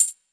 Urban Tamb 02.wav